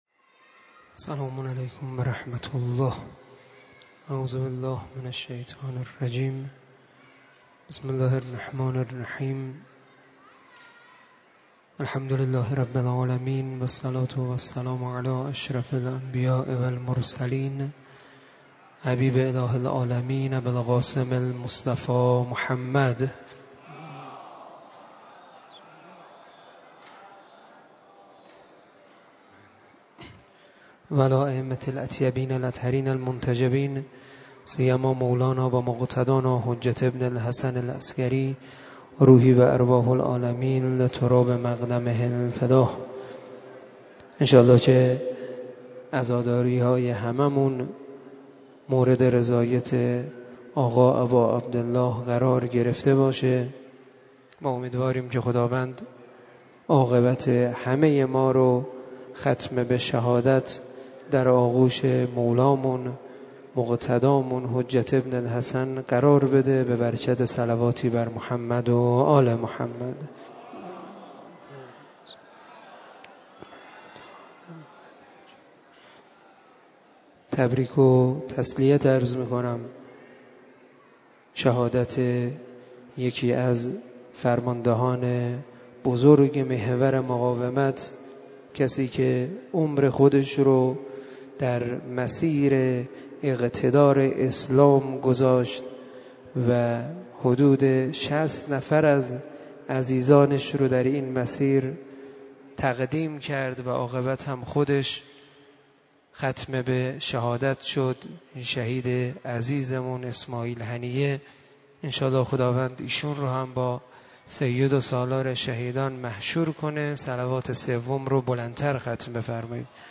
سخنرانی
عوامل ریزش‌ها و رویش‌ها - شب دوم در حرم مطهر حضرت شاهچراغ علیه السلام